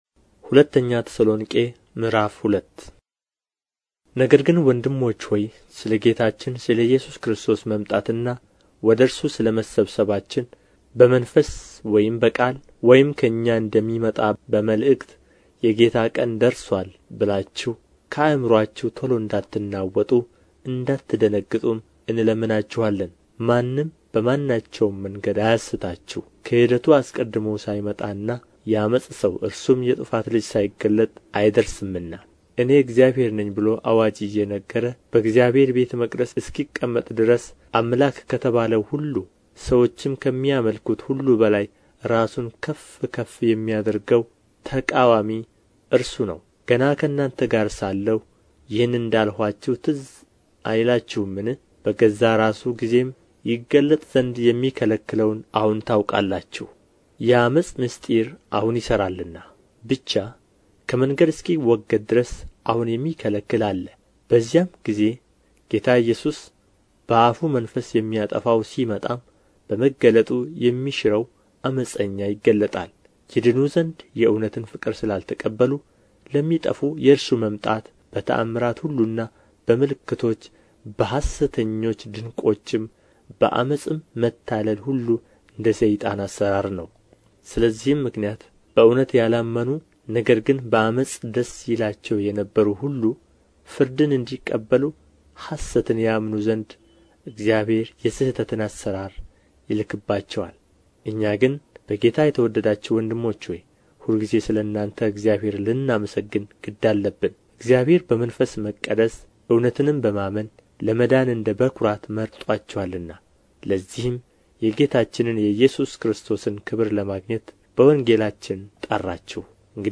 ምዕራፍ 2 ንባብ